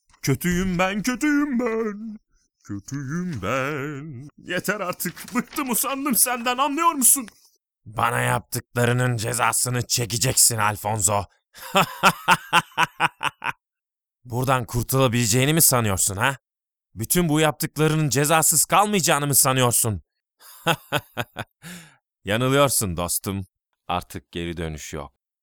Sprechprobe: Sonstiges (Muttersprache):
male voice over artist in turkish german english